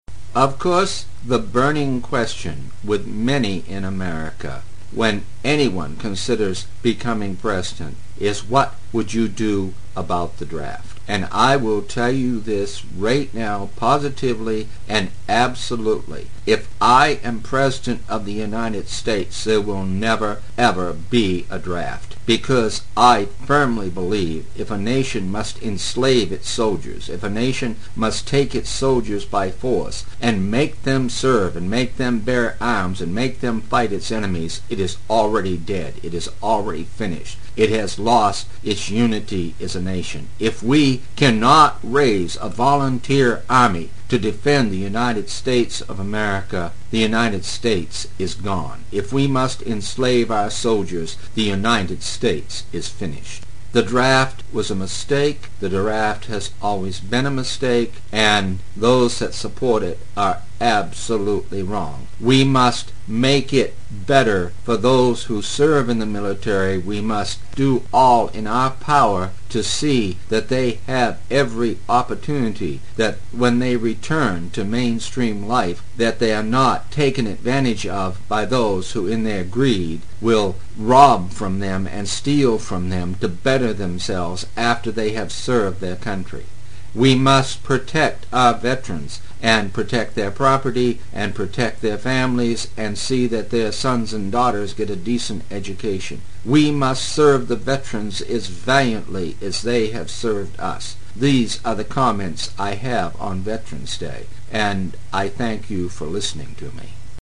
Veterans Day Speech 2,007